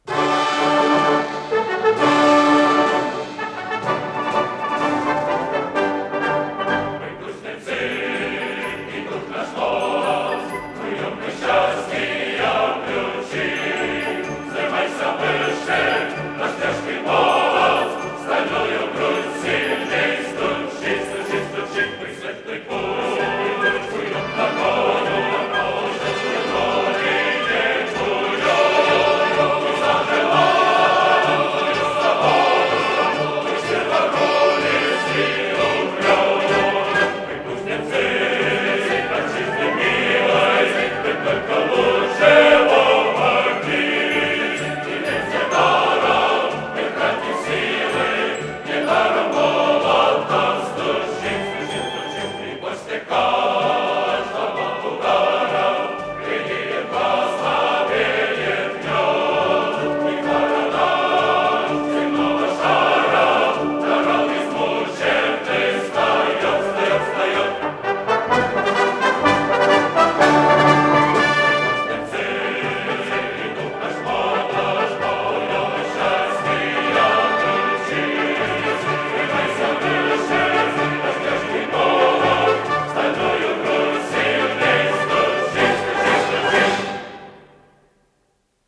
с профессиональной магнитной ленты
ИсполнителиГосударственный академический мужской хор Эстонской ССР
АккомпаниментДуховой оркестр "Хеликон"
ВариантДубль моно